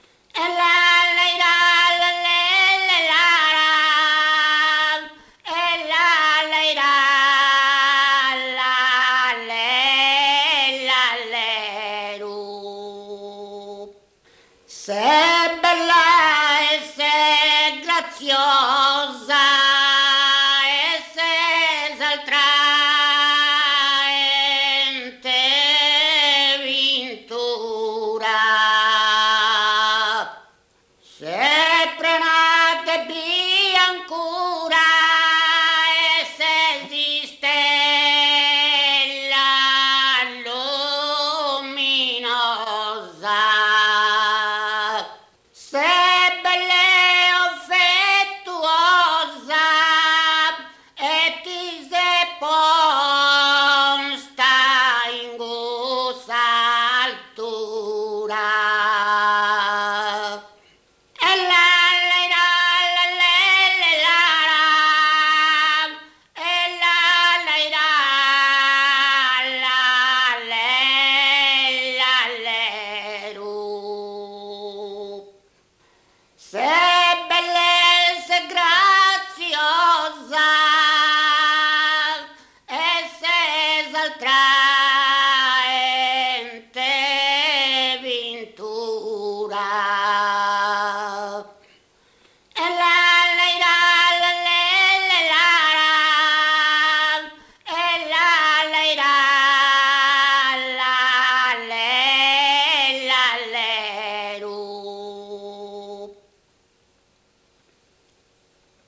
muttetu